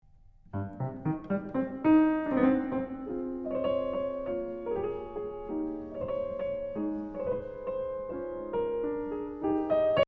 It’s obviously a more calm, lyrical part, but do we mess up the theme too much by using pedal?
So, a fast and light foot is needed there, using half-pedal to adjust quickly to what the ears catch.
Bar 10-14: I think the result was very good, very enjoyable.
halfpedal.mp3